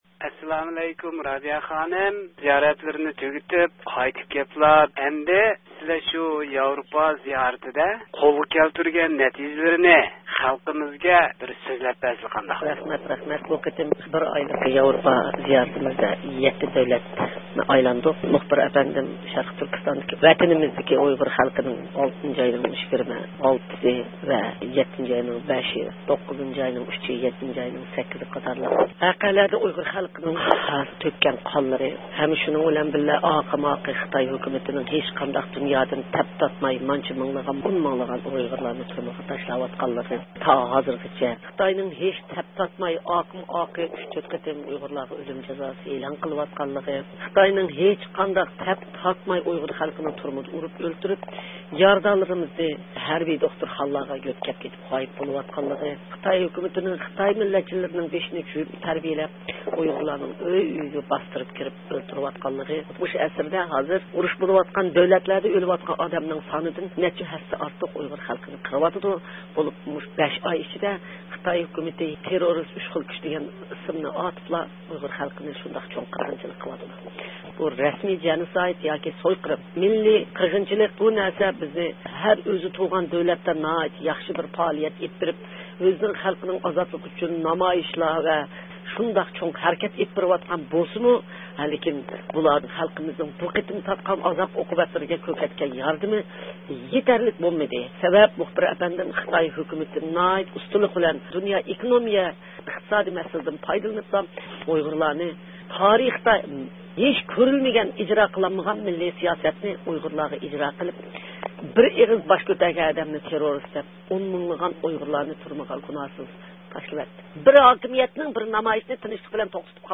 ئۇيغۇر رەھبىرى رابىيە قادىر خانىم بىر ئايلىق ياۋروپا زىيارىتىنى ئاياقلاشتۇرۇپ ئامېرىكىغا قايتىپ كەلگەندىن كېيىنلا، ئۆزىنىڭ بۇ قېتىمقى ياۋروپا زىيارىتى ۋە ھازىر ئۇيغۇرلار دۇچ كېلىۋاتقان مەسىلىلەرنى ھەل قىلىش ئۈچۈن ئېلىپ بېرىۋاتقان پائالىيەتلىرى ھەققىدە مۇخبىرىمىزنىڭ سوئاللىرىغا جاۋاب بەردى.
ئۇيغۇر رەھبىرىرابىيە قادىر خانىم بىر ئايلىق ياۋروپا زىيارىتىنى ئاياقلاشتۇرۇپ ئامېرىكىغا قايتىپ كەلگەندىن كېيىنلا، بۈگۈن ئۆزىنىڭ بۇ قېتىمقى ياۋروپا زىيارىتى ھەققىدە رادىئو ئاڭلىغۇچىلىرىمىزغا مەلۇمات سۆزلەپ بەردى.